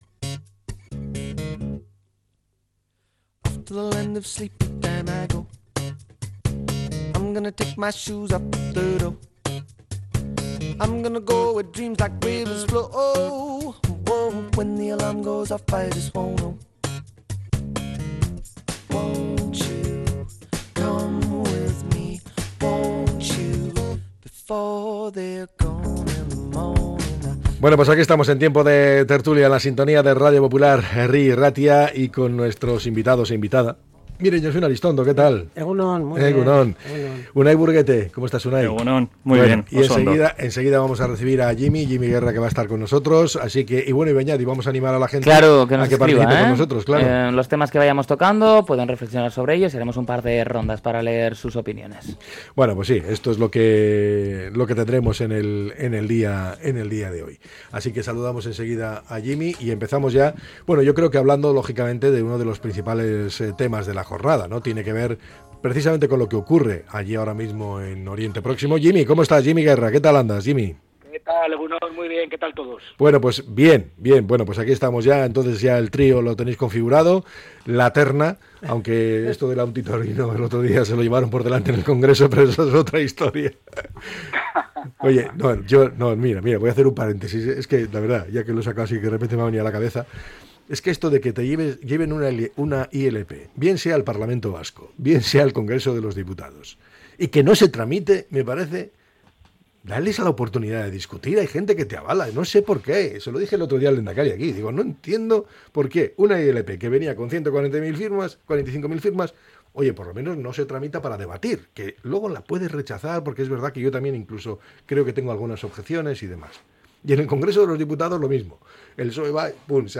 La tertulia 09-10-25.